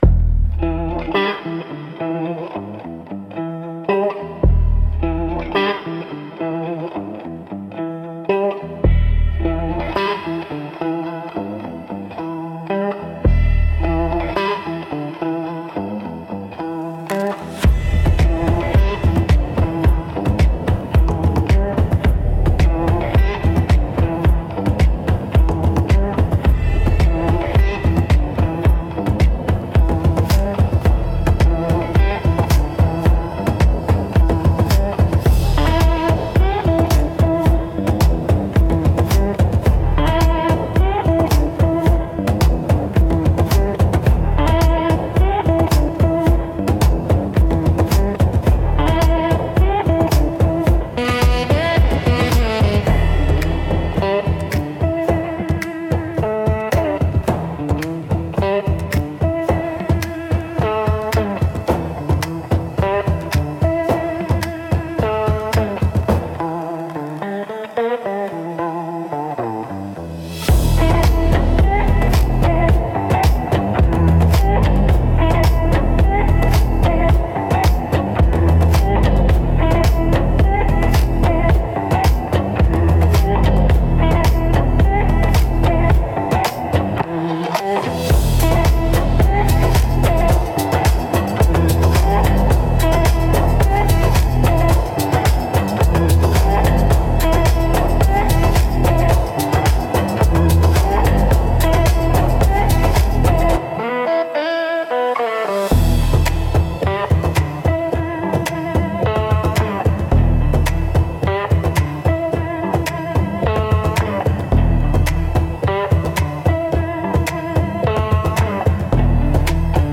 Instrumental - Velvet Venom 3.33